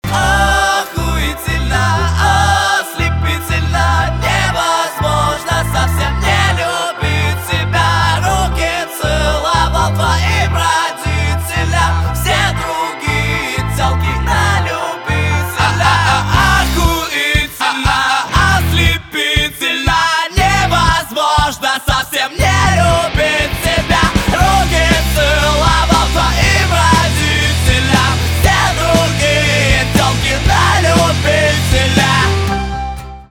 русский рок
романтические
гитара , барабаны